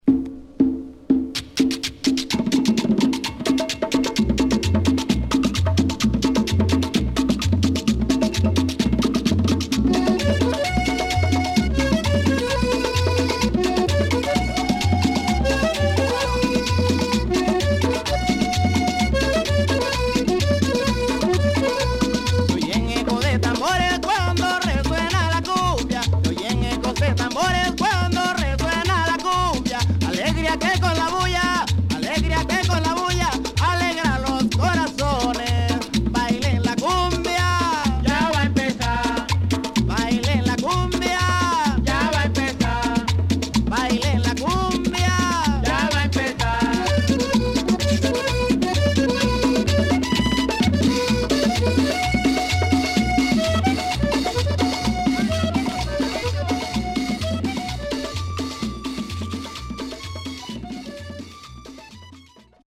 パーカッション主体にアコーディオンのメロディーライン。そしてベースラインはレゲエにも似た所があるというクンビア。